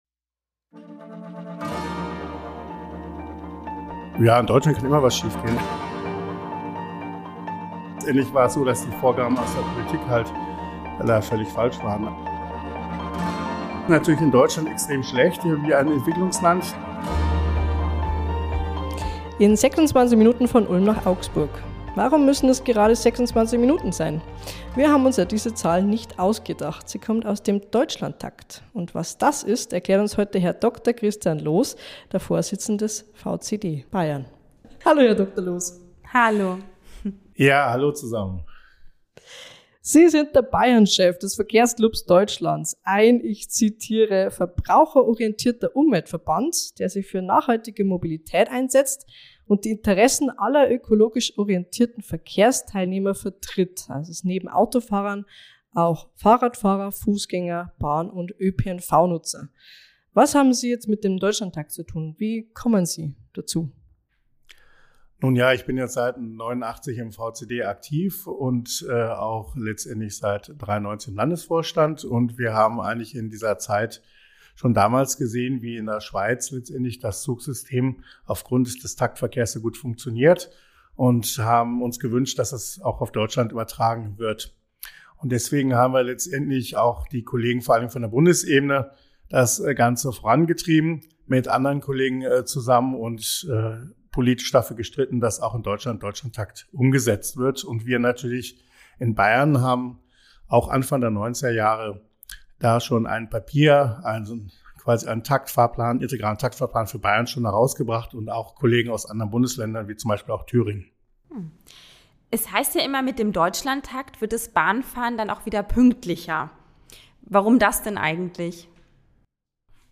Beschreibung vor 2 Jahren Seit den 90ern setzt sich der Verkehrsclub Deutschland (VCD) für einen integralen Taktfahrplan nach Schweizer Vorbild ein. Ein Gespräch